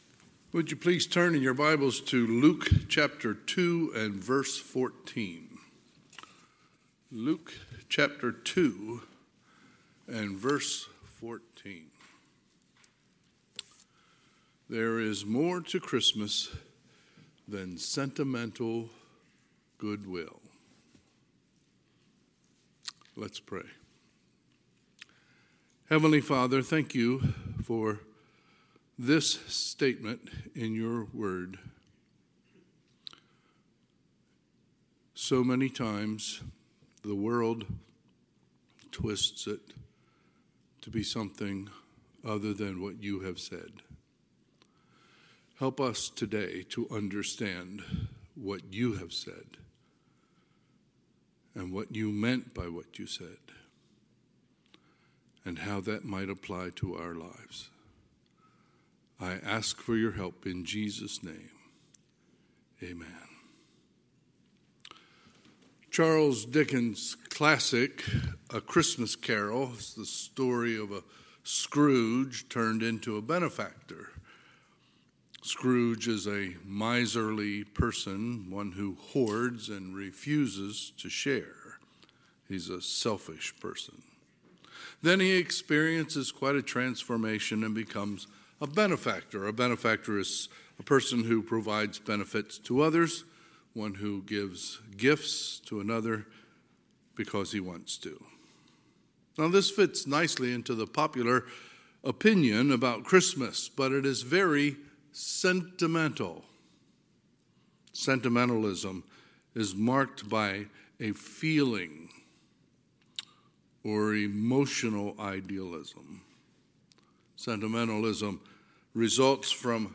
Sunday, December 15, 2024 – Sunday AM
Sermons